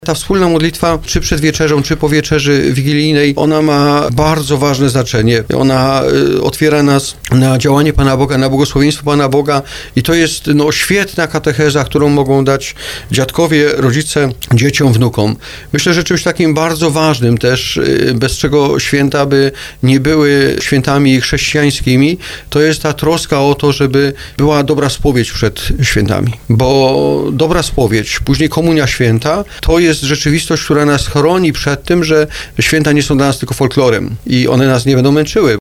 Biskup pomocniczy diecezji tarnowskiej, który był gościem audycji Rozgryźć Kościół mówił o przygotowaniach do Świąt Bożego Narodzenia.